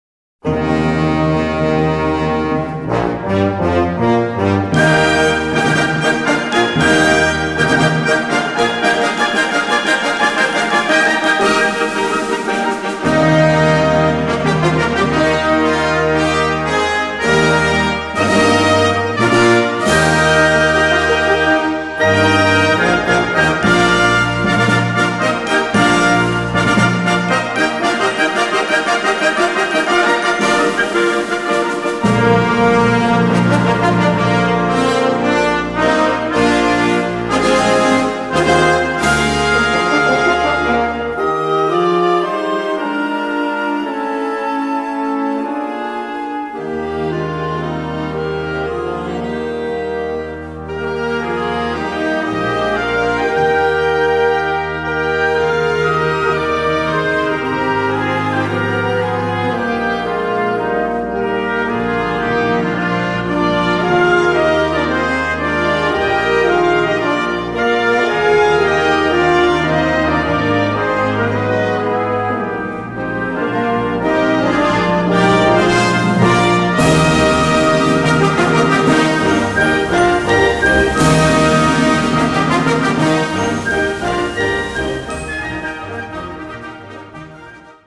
for Concert Band